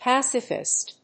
音節pác・i・fist 発音記号・読み方
/‐fɪst(米国英語)/